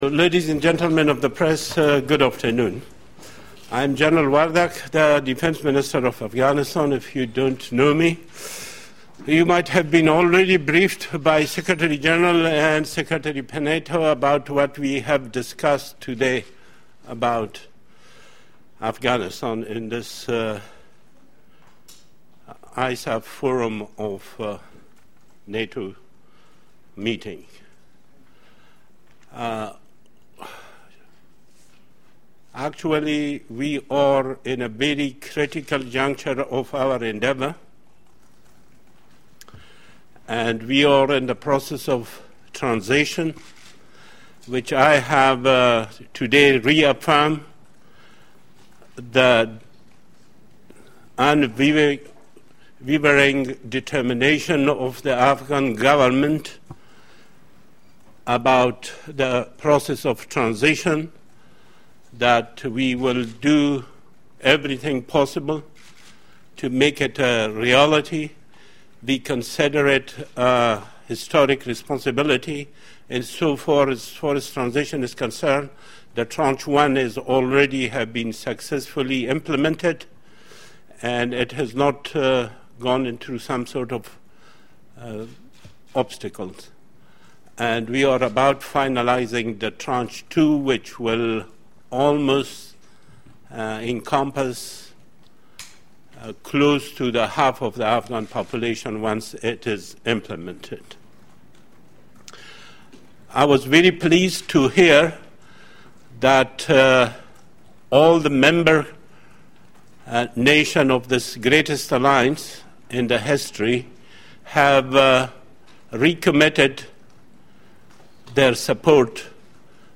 Press conference by Adbul Rahim Wardak, Defence Minister of Afghanistan after the meeting of NATO Ministers of Defence with non-NATO ISAF contributing nations